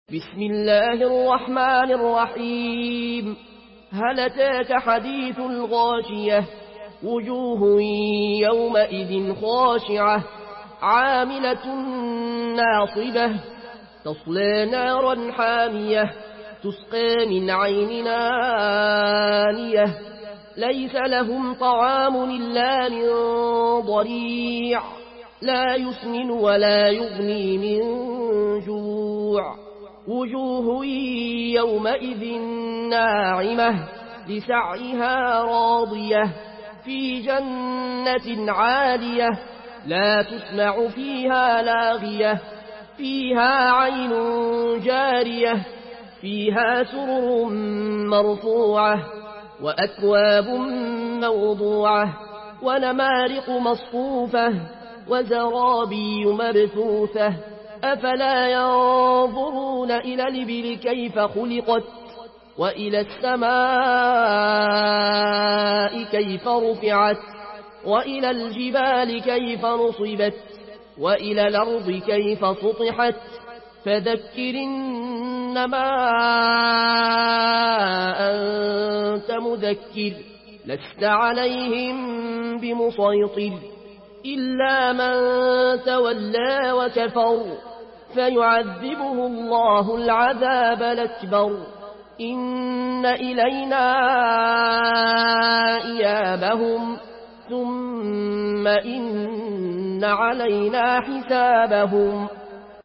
مرتل ورش عن نافع من طريق الأزرق